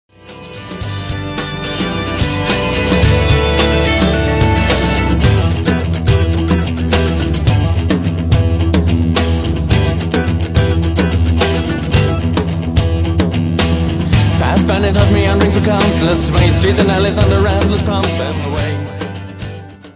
Celtic Rock